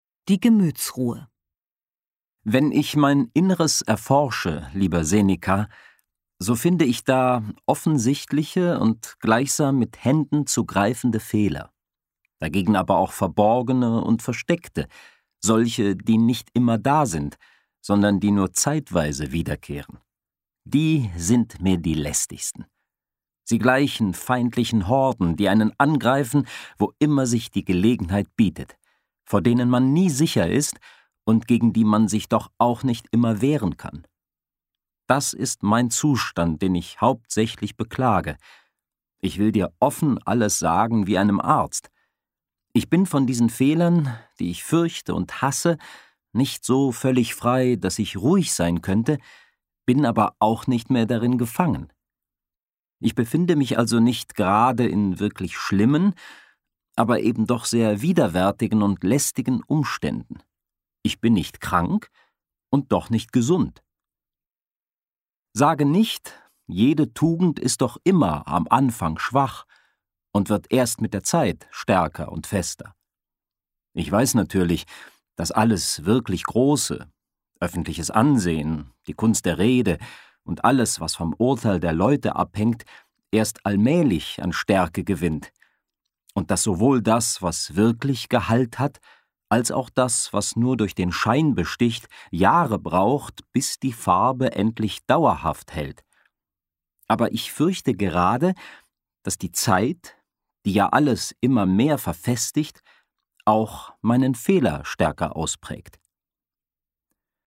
Auf dem Weg hin zu einem ausgeglichenen Wesen, zur Gemütsruhe, begleiten wir in diesem Hörbuch Serenus und Seneca.
horprobe-die-gemutsruhe.mp3